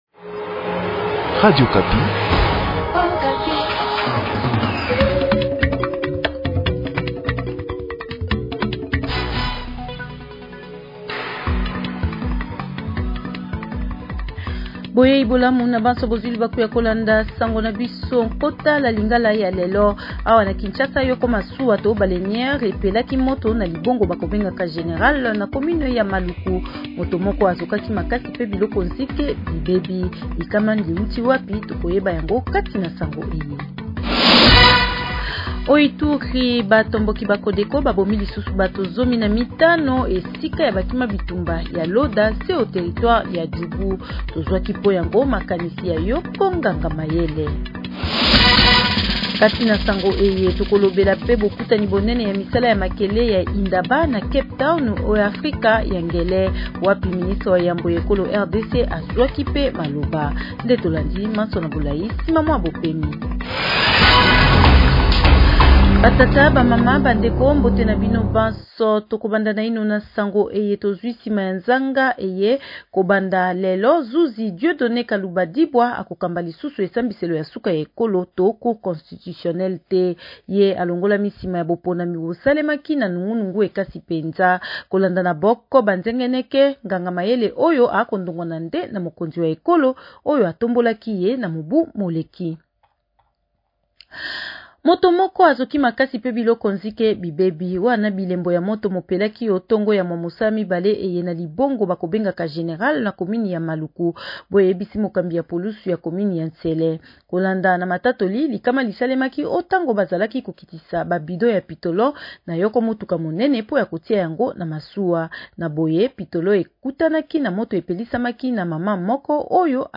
Journal Lingala Soir